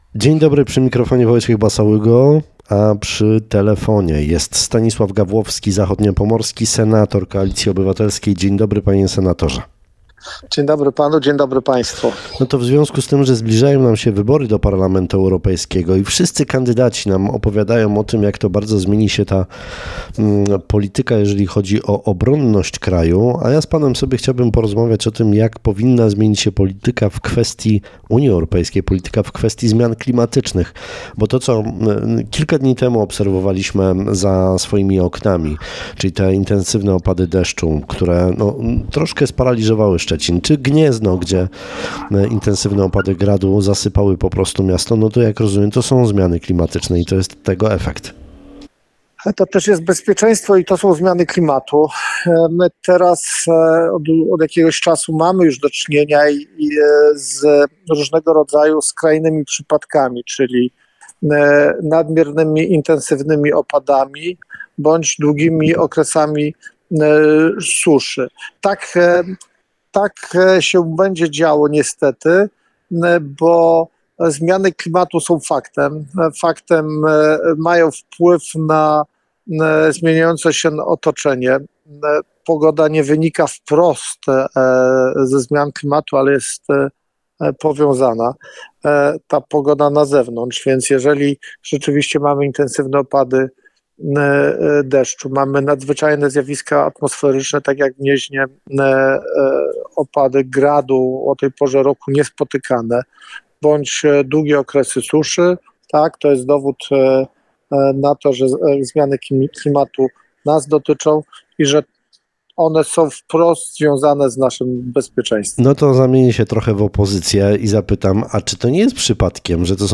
Opłata mocowa zniknie z naszych rachunków za prąd na pół roku. Mówił o tym na antenie Twojego Radia Stanisław Gawłowski, zachodniopomorski senator Koalicji Obywatelskiej – autor senackiej poprawki.